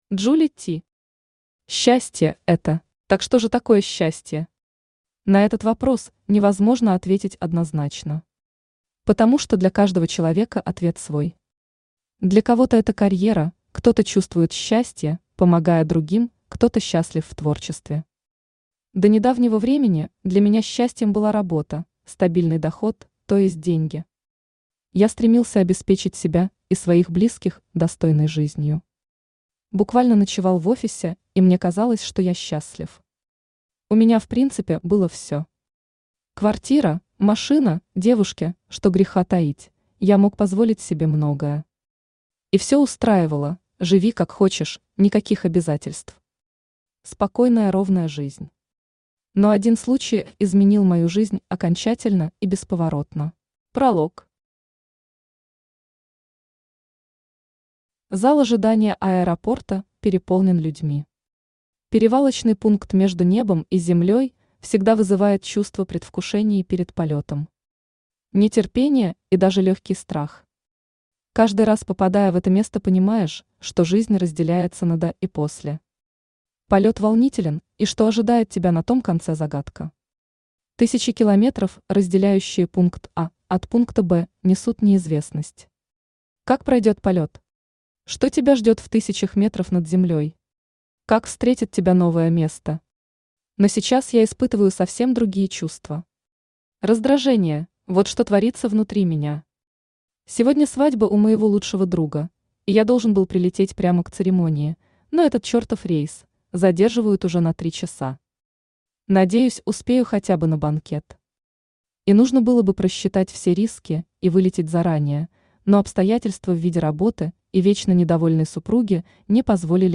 Автор Джули ТИ Читает аудиокнигу Авточтец ЛитРес.